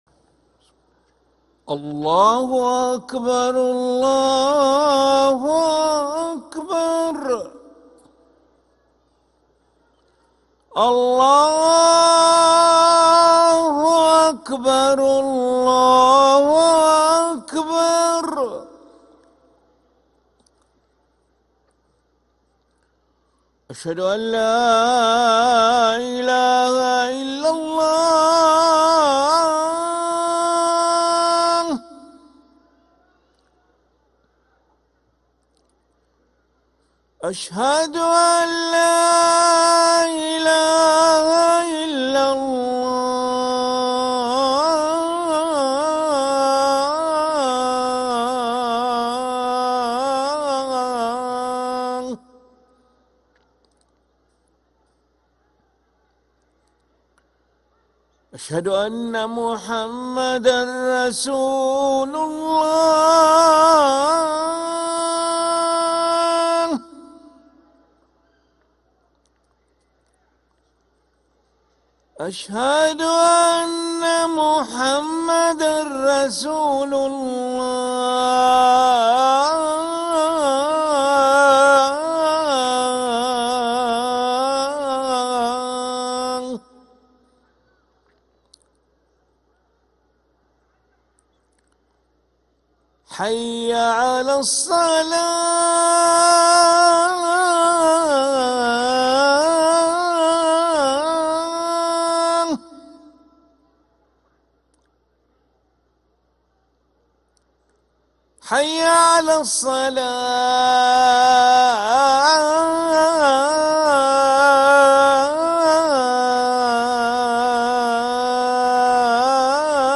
أذان العشاء للمؤذن علي ملا الأحد 7 جمادى الآخرة 1446هـ > ١٤٤٦ 🕋 > ركن الأذان 🕋 > المزيد - تلاوات الحرمين